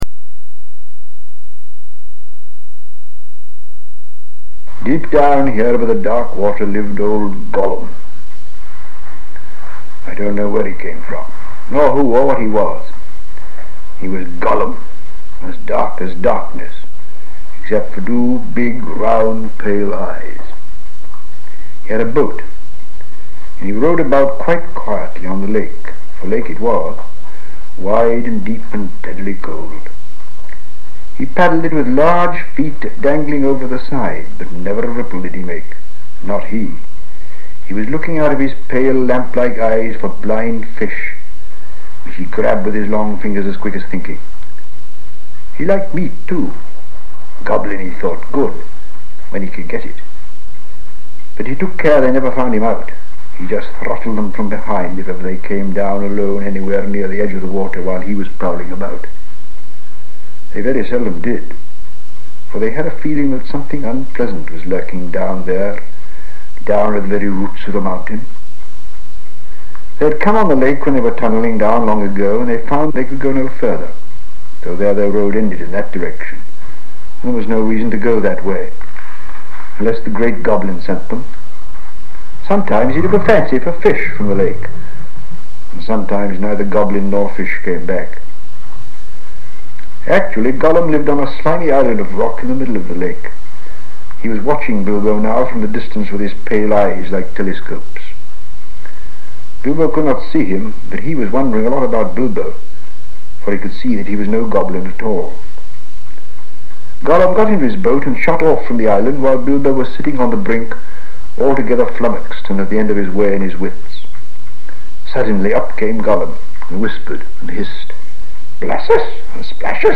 Tape 1 Side 1 The Hobbit - excerpts read by JRR Tolkien - آردا، دنیای تالکین